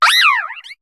Cri de Mesmérella dans Pokémon HOME.